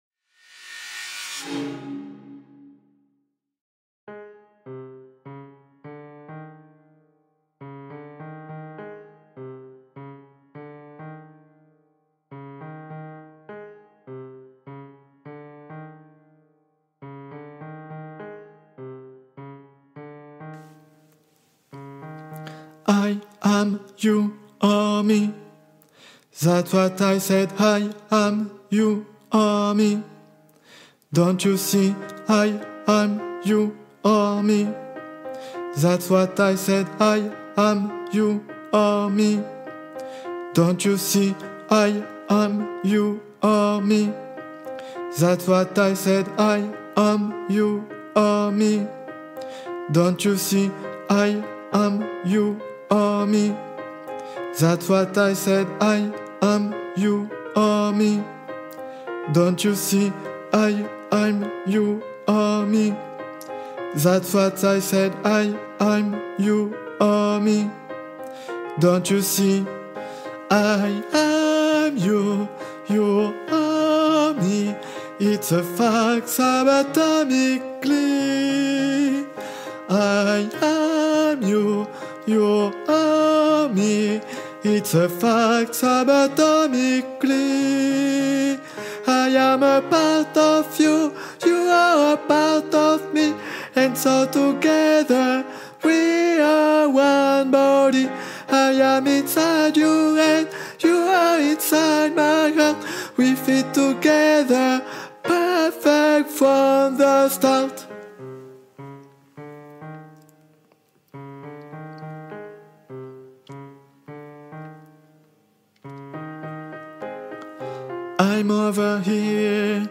Guide Voix Tenors (version chantée)